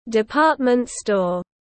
Department store /dɪˈpɑːt.mənt ˌstɔːr/
Department-store.mp3